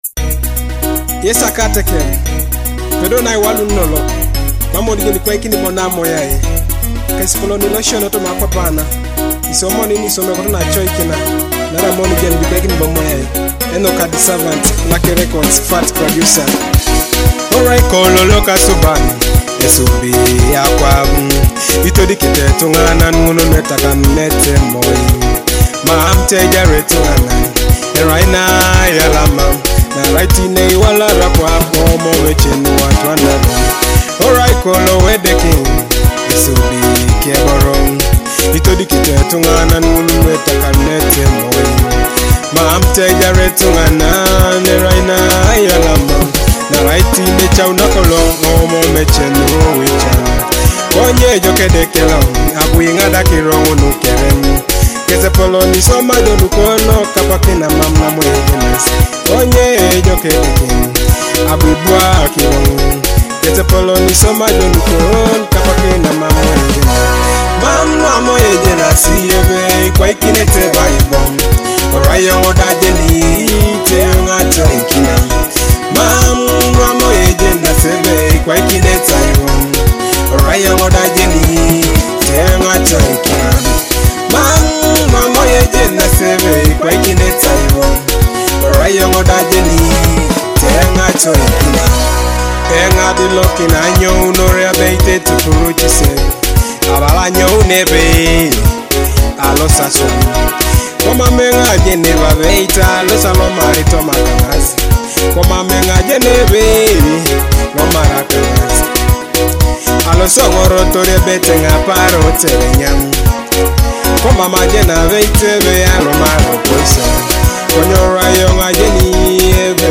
Teso gospel music